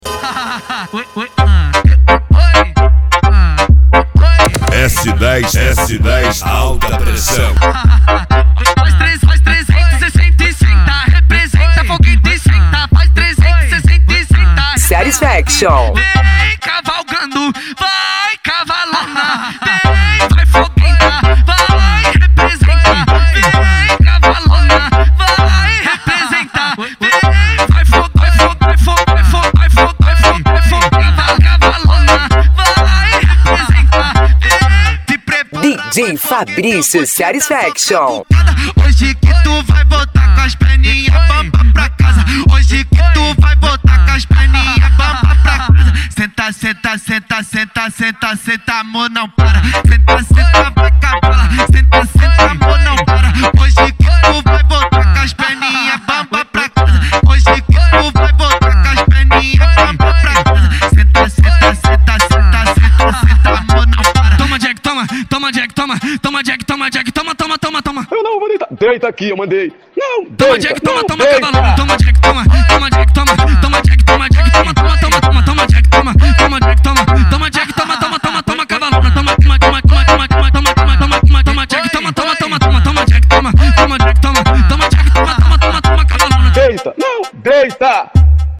Bass
Funk